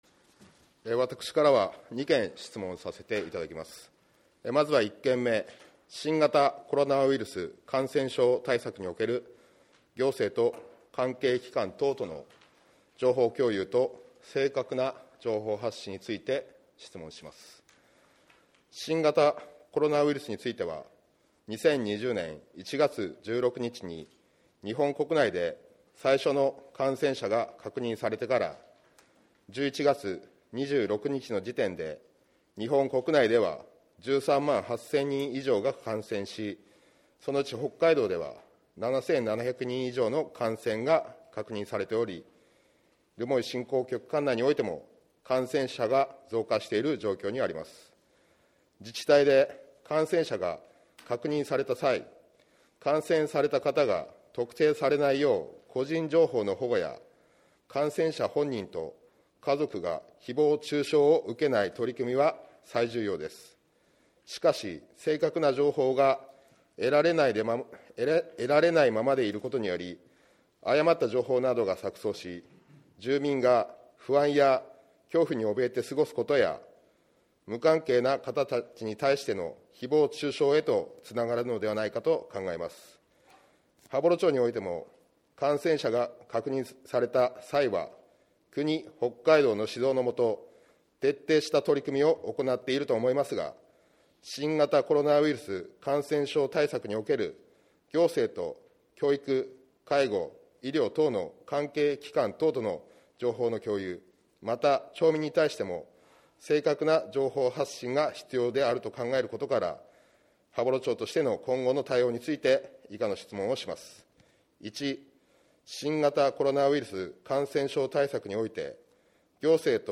定例会での「一般質問」の様子を音声で提供します。